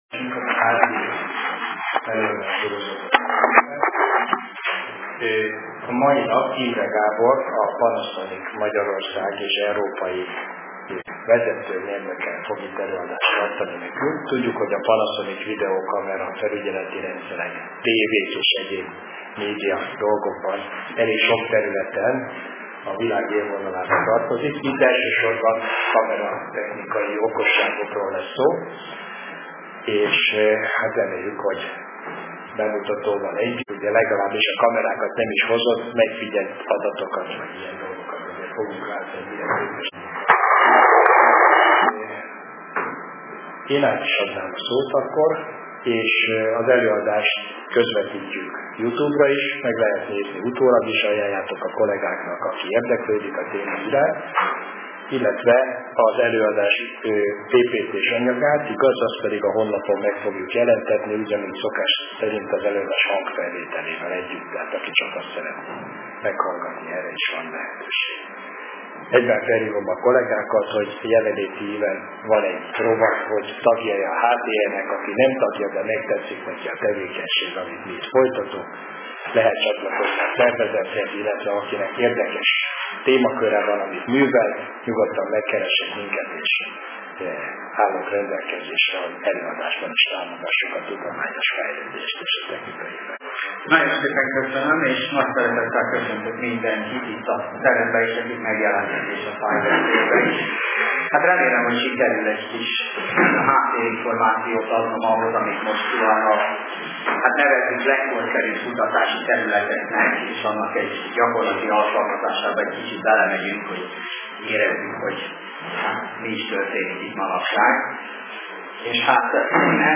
A Vételtechnikai és a Kábeltelevíziós Szakosztály valamint a Médiaklub meghívja az érdeklődőket az alábbi igen érdekes és időszerű előadásra